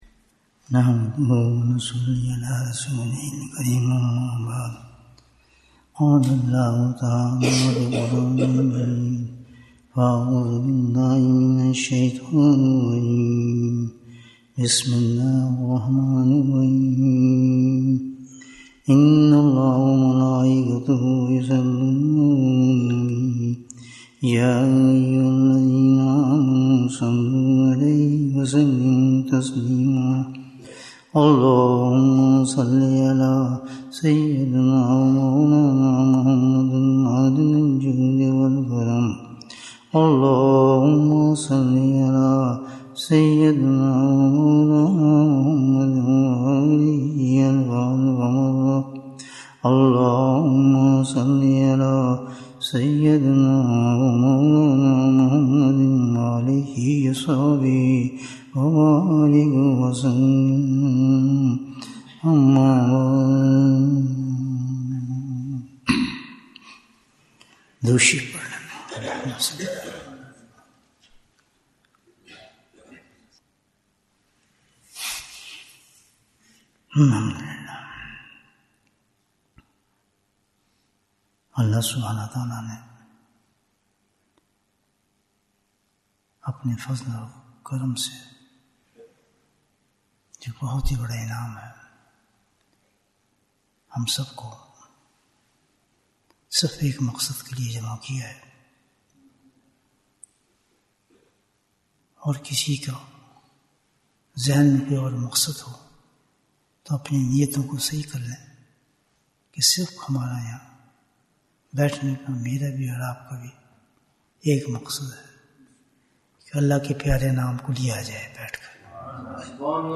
اس دنیا سے کیسے فائدہ ااٹھاہیں؟ Bayan, 45 minutes11th July, 2024